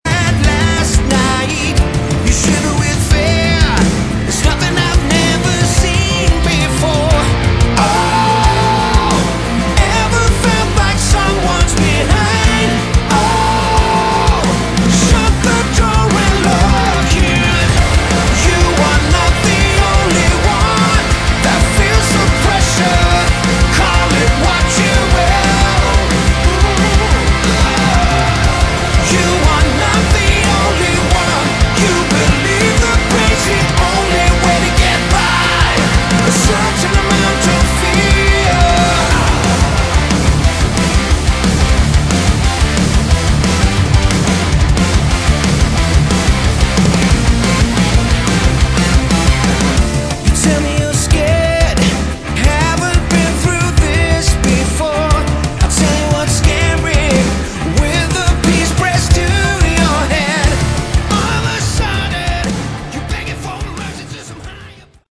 Category: Melodic Rock
bass, vocals
drums
keyboards
guitars